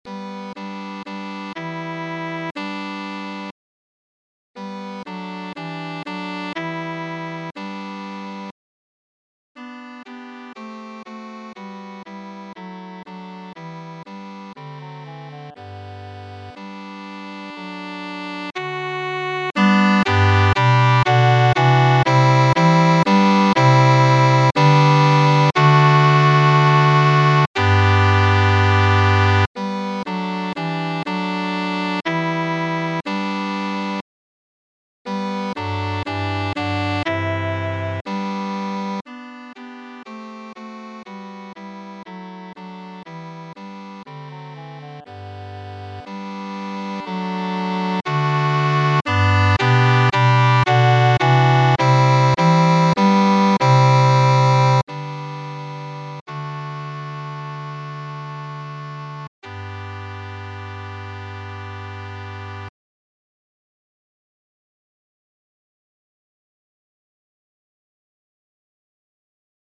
TTBB (4 voces Coro de hombres )
Tonalidad : sol mayor